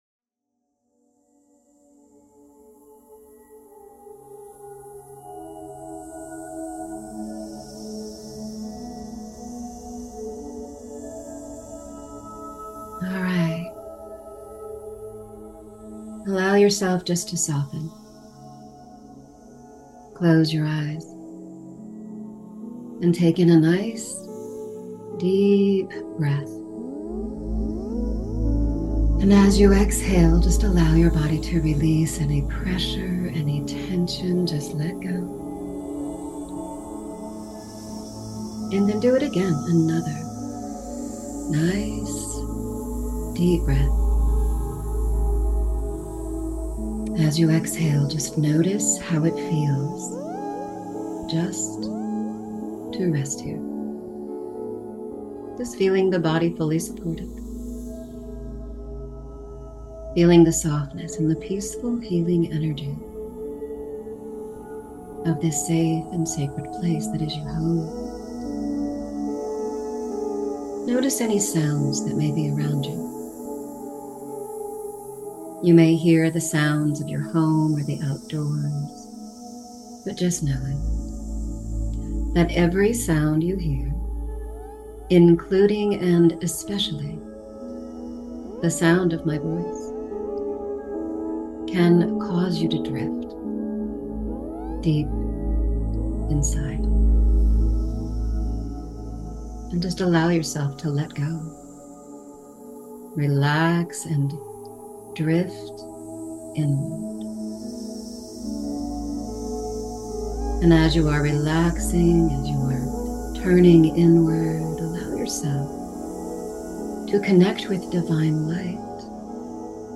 Guided hypnosis/regression session to help you regress back to times when you were your most prosperous, healthy, happy, and clear on your path and bring that energy into the NOW!